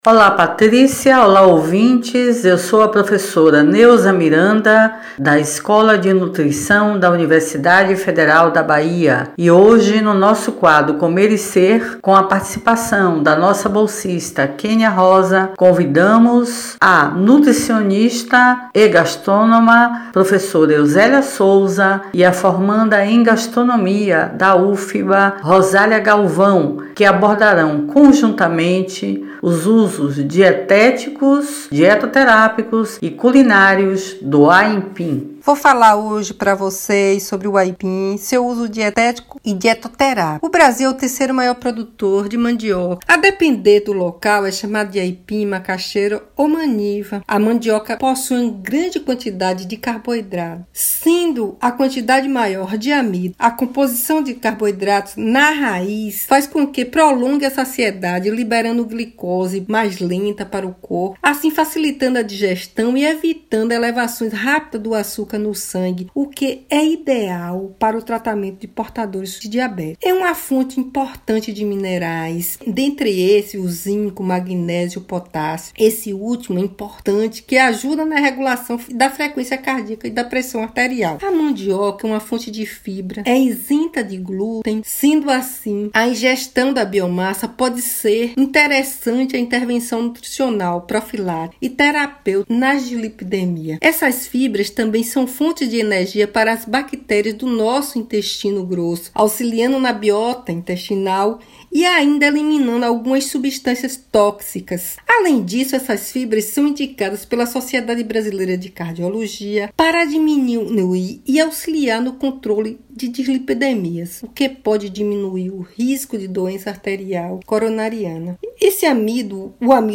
Ouça o comentário da nutricionista, gastrônoma e professora